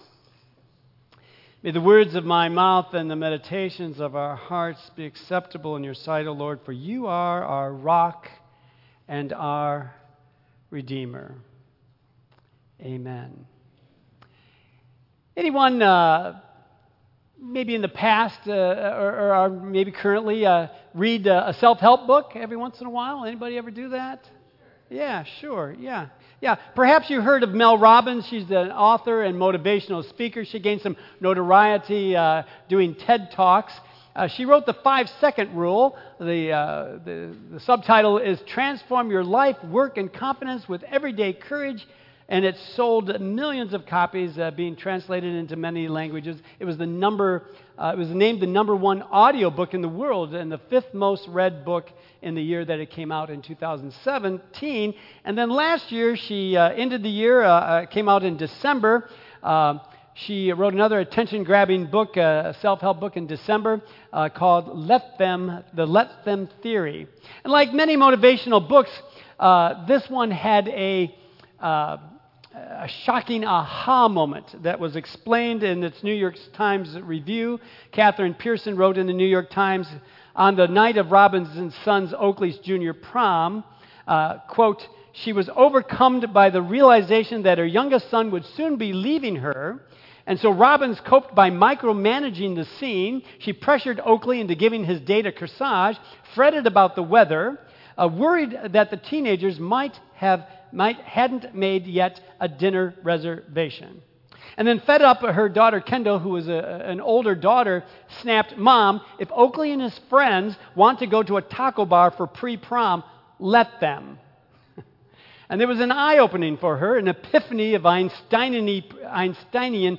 Tagged with Michigan , Sermon , Waterford Central United Methodist Church , Worship Audio (MP3) 9 MB Previous The Samaritan Woman at the Well Next A Father-In-Law's Advice